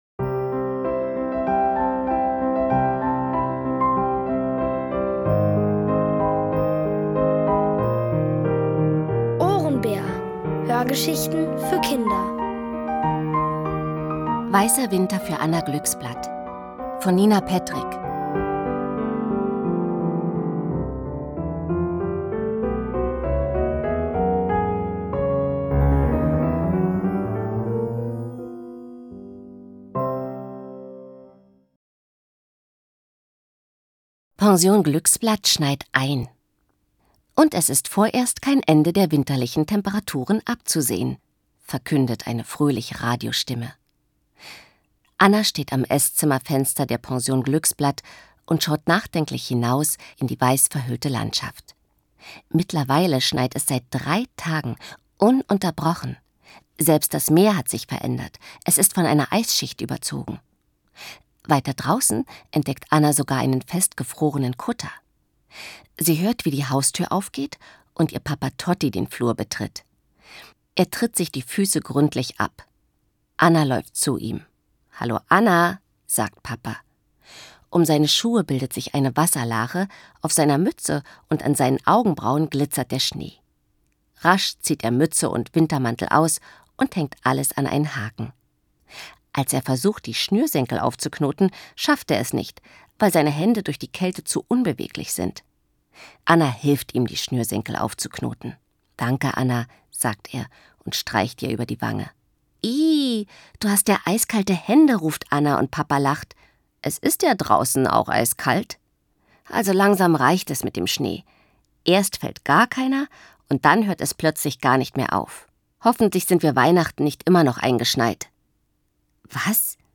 Von Autoren extra für die Reihe geschrieben und von bekannten Schauspielern gelesen.
Hörgeschichten empfohlen ab 6: